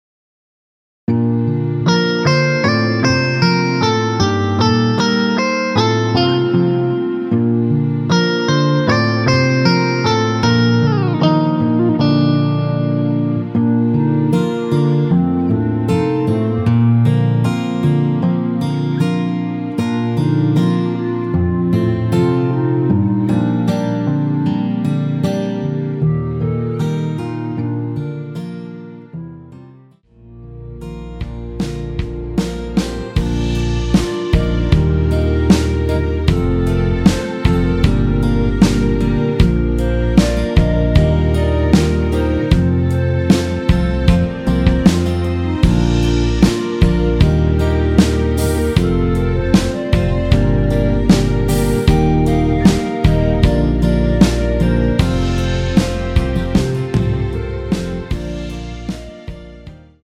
원키에서(-3)내린 멜로디 포함된 MR입니다.
Bb
앞부분30초, 뒷부분30초씩 편집해서 올려 드리고 있습니다.